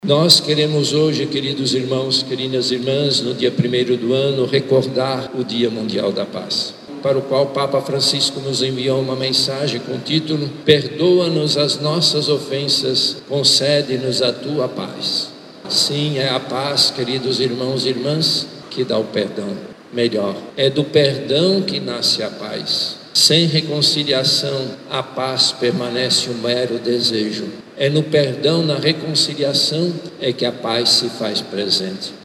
Na manhã desta quarta-feira, 01 de janeiro de 2025, em que a Igreja celebra a Solenidade de Maria Mãe de Deus e o Dia Mundial da Paz, a comunidade católica de Manaus esteve presente na celebração presidida pelo Cardeal Steiner, na Catedral Metropolitana.
SONORA-2-LEONARDO-STEINER-missa.mp3